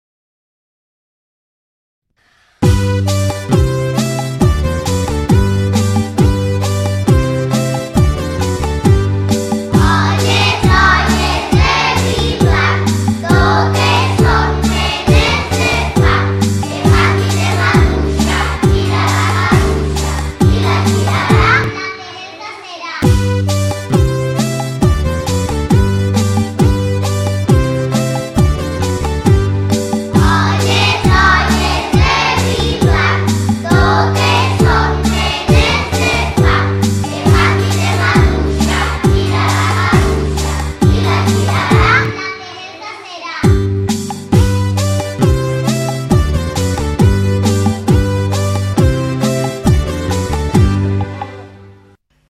“OLLES, OLLES”, cantat per alumnes de p-5 (3 classes de 24-25 alumnes)